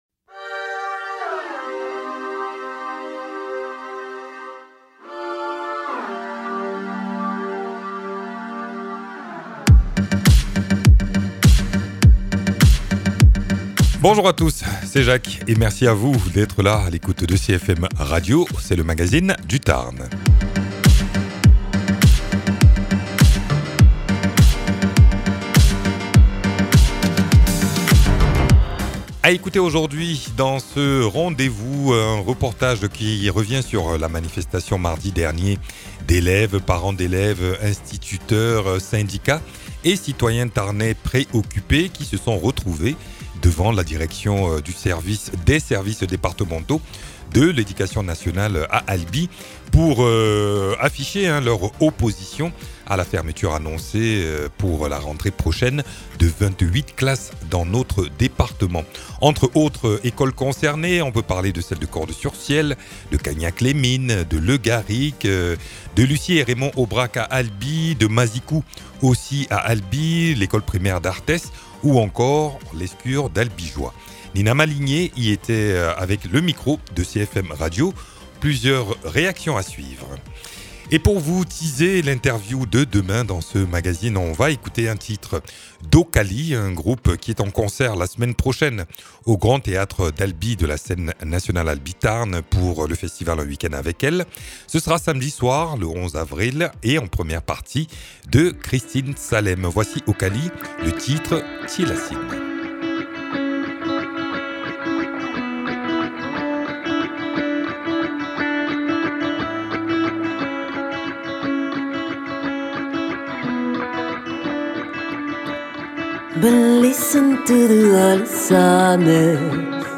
Sifflets, pancartes et prises de parole devant la DSDEN à Albi : mardi dernier, enseignants, parents d’élèves, syndicats et élèves se sont réunis pour dénoncer la fermeture annoncée de 28 classes dans le Tarn à la rentrée prochaine. Entre inquiétude, colère et appels au dialogue, les voix du cortège se sont exprimées au micro de CFM Radio. Reportage au cœur de la mobilisation.
Invité(s) : Syndicats, parents d’élèves, instituteurs, élèves.